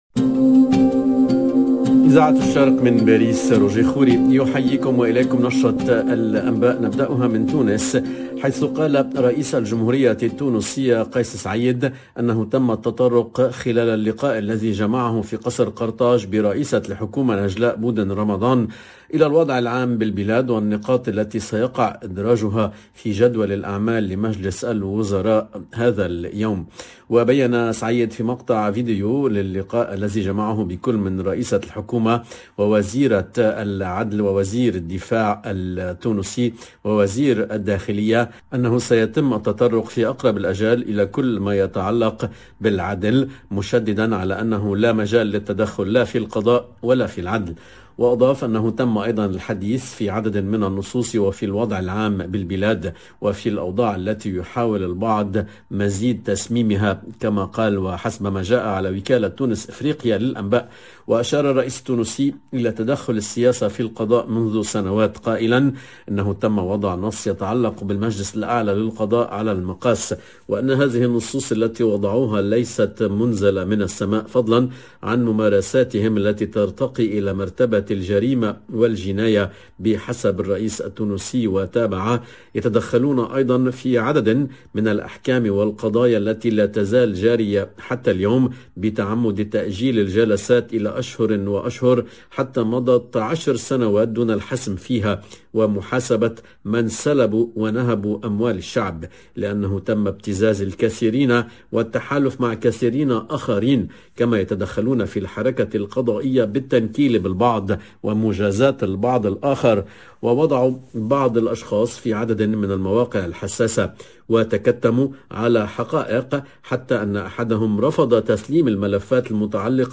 LE JOURNAL DE MIDI 30 EN LANGUE ARABE DU 6/01/22